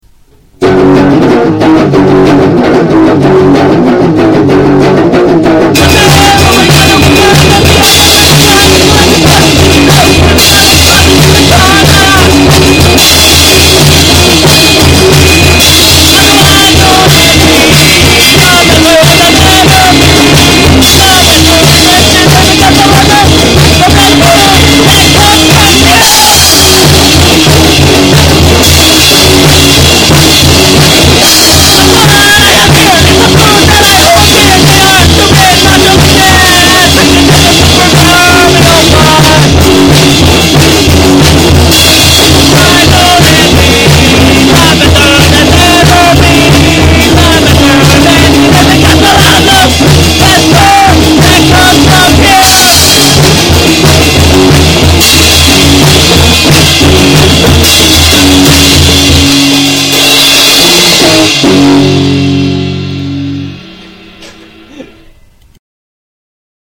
full of awesome covers and fourtrack excellence.
for fans of loud and/or fast-paced songs.